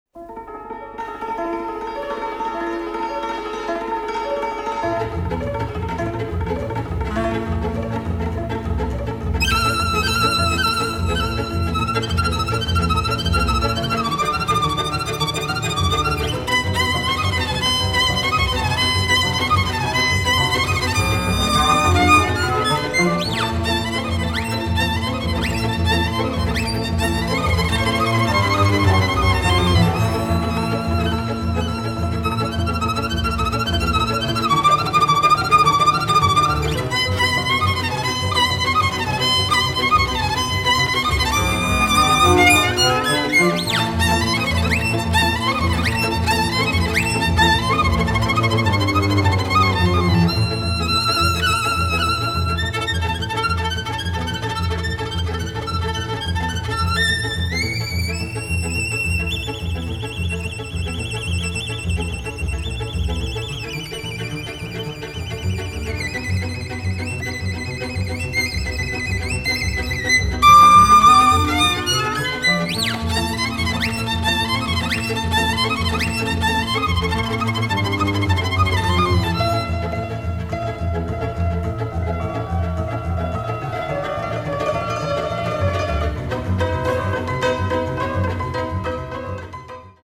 イイです！1954年から59年にかけて録音されたハンガリー系ロマ音楽のヴィンテージ録音を集めた3枚組アンソロジー！
ストリングスを主体とするハンガリアン・スタイルなアンサンブルがとてもイイですね！
ダンサブルな音源や、ホロッとくる叙情的な音源、ツィンバロムのソロ、それから合唱なども収録されています！